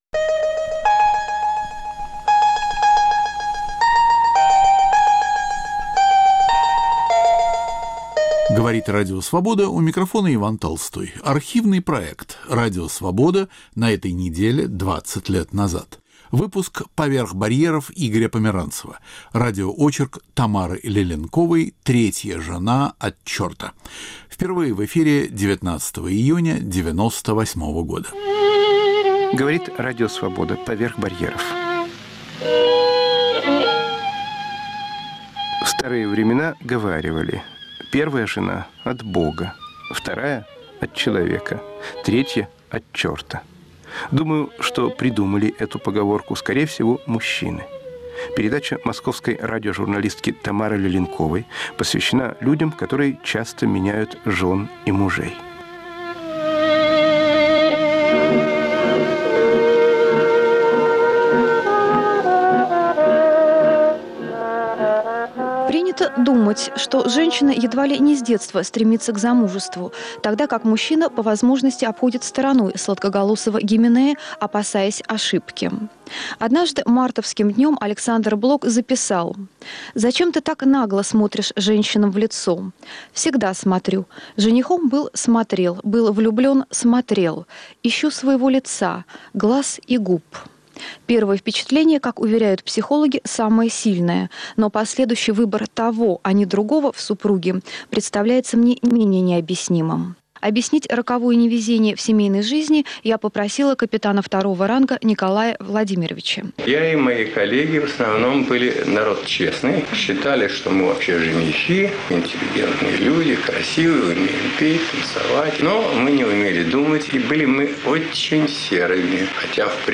Архивный проект. Иван Толстой выбирает из нашего эфира по-прежнему актуальное и оказавшееся вечным.